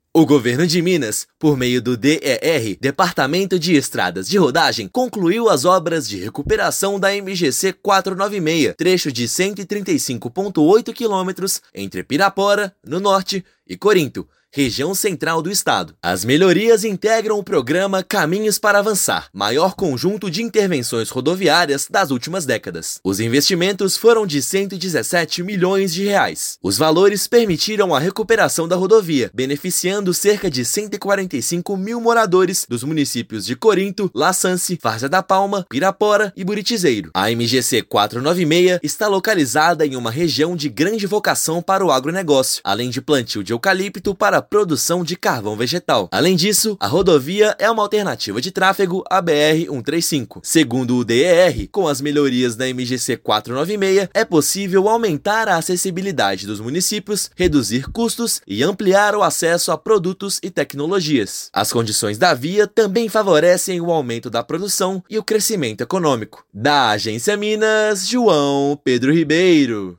Trecho de 135 quilômetros, que passou por obras no pavimento e ganhou nova sinalização, beneficia diretamente mais de 140 mil habitantes das regiões Norte e Central. Ouça matéria de rádio.